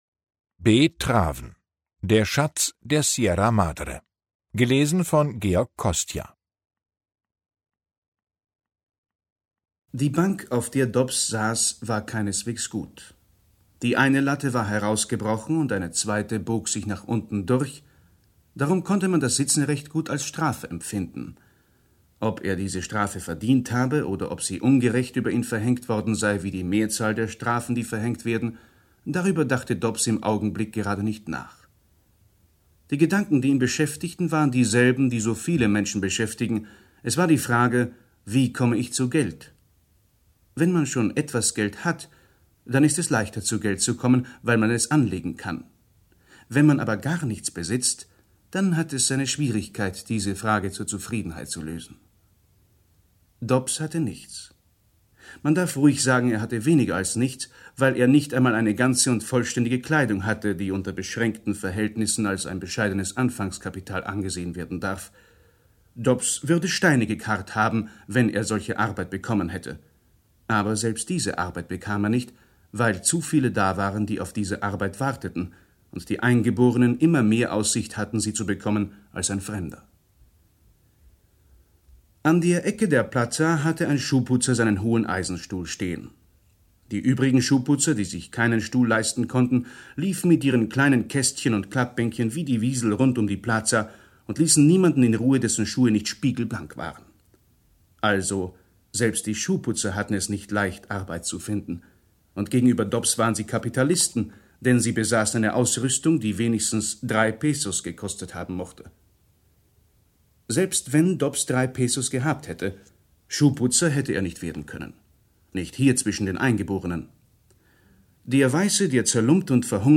»Die Hörbuch-Edition ›Große Werke. Große Stimmen.‹ umfasst herausragende Lesungen deutschsprachiger Sprecherinnen und Sprecher, die in den Archiven der Rundfunkanstalten schlummern.« SAARLÄNDISCHER RUNDFUNK